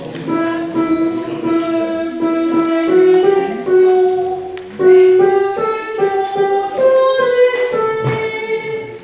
Mélodie